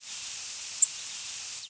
Short rising seeps
Vesper Sparrow ex1